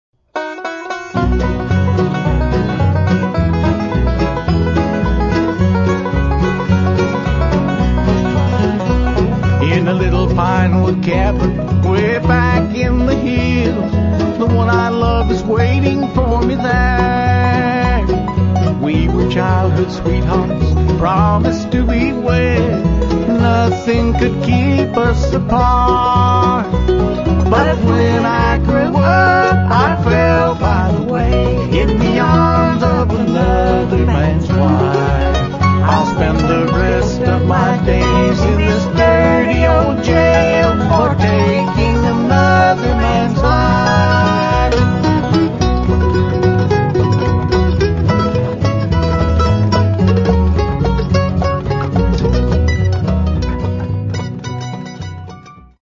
Lead & Harmony Vocals, Mandolin, Fiddle, Guitar
Harmony Vocals, Upright Bass
Lead & Rhythm Guitar
Banjo